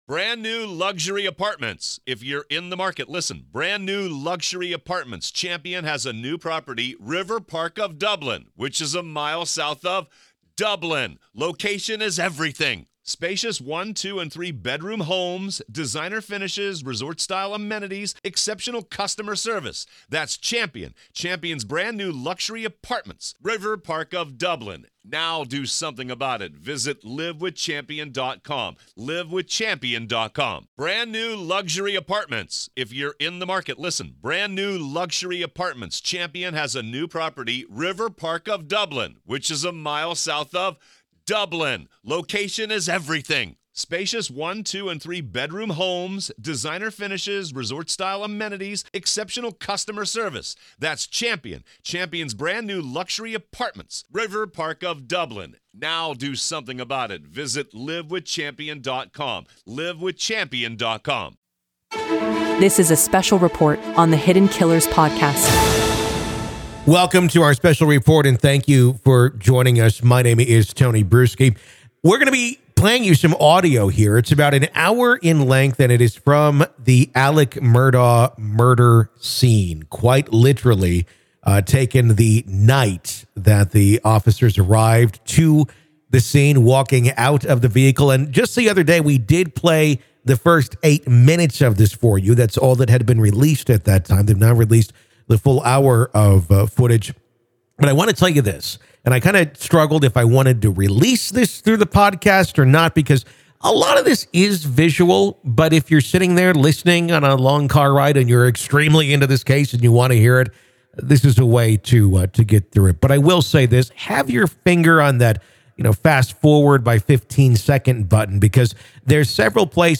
Get ready to witness the haunting moments as police investigate the brutal murder of Alex Murdaugh's wife and son. On June 7, 2021, bodycam footage captured the harrowing scene as law enforcement descended upon Murdaugh's South Carolina home.
Despite being redacted by the court, the footage still manages to reveal the mix of emotions Murdaugh experiences as he speaks with the officers while they secure the crime scene.